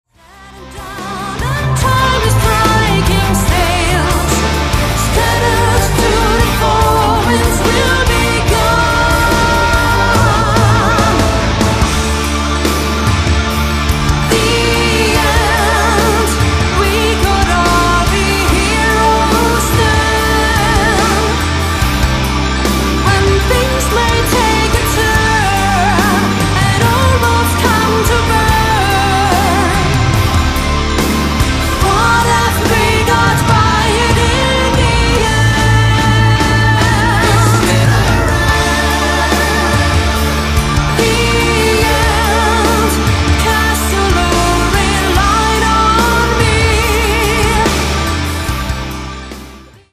* Symphonic Power Metal *